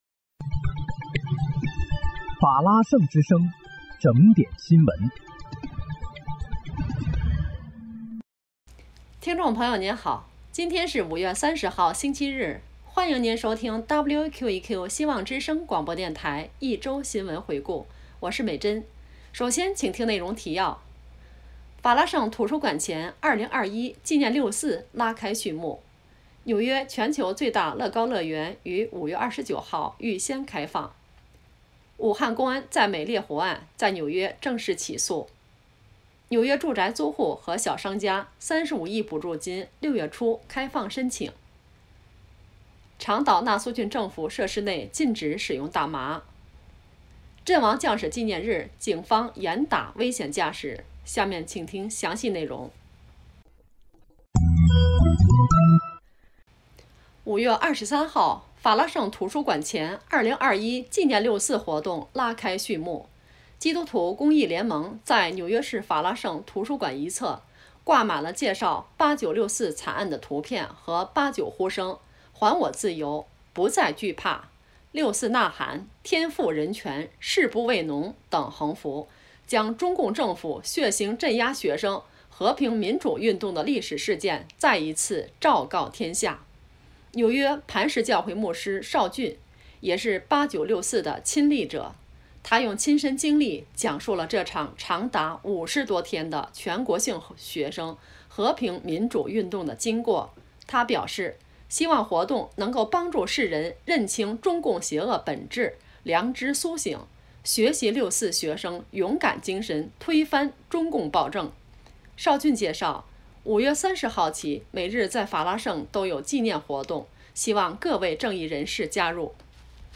5月30日（星期日）一周新闻回顾